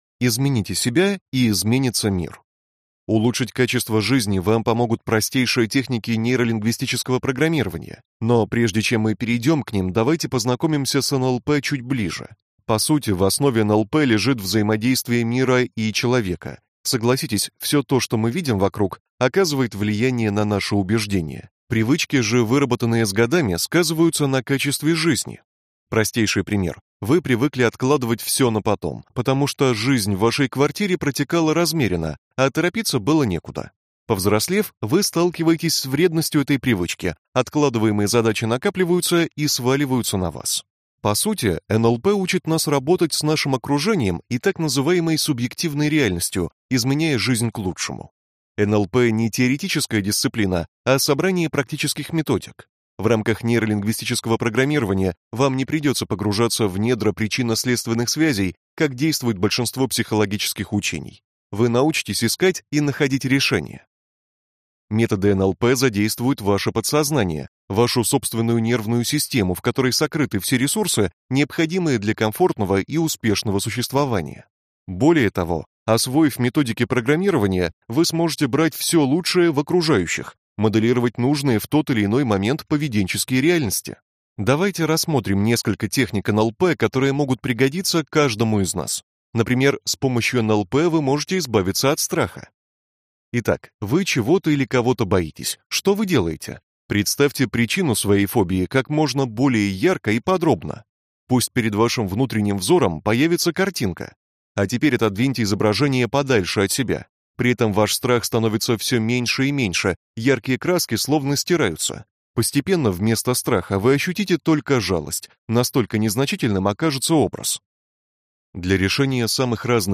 Аудиокнига НЛП. Разговорный гипноз | Библиотека аудиокниг